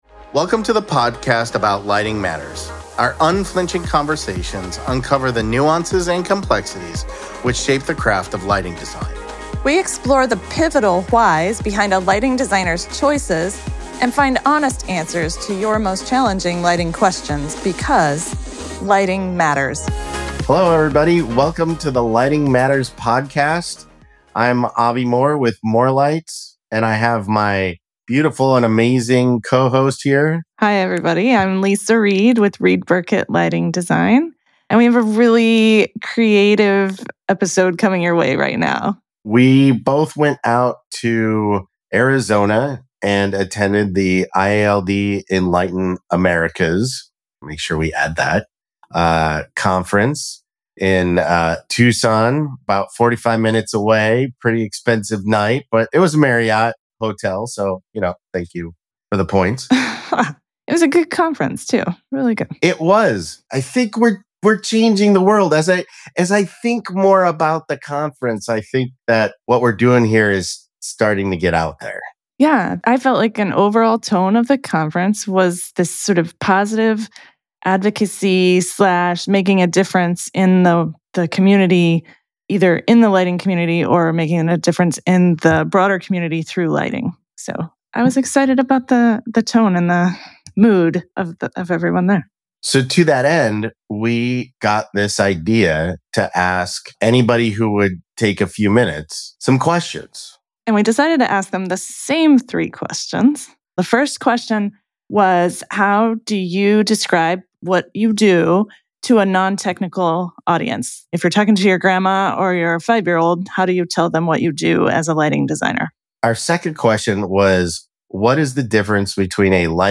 Ten experienced lighting designers walk into a conference. Can they agree on what they actually do for a living?"What do you do?"